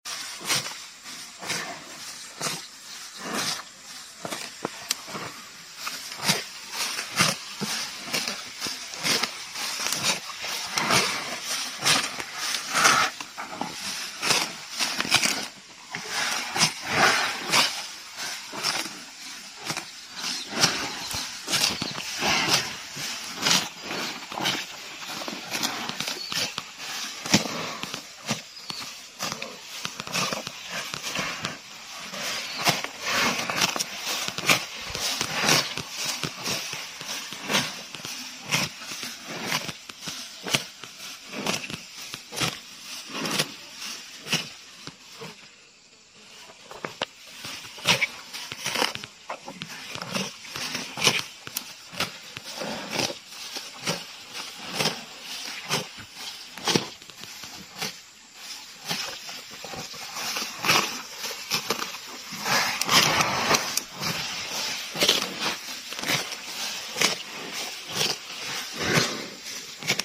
My Cow Eating Grass Sound Effects Free Download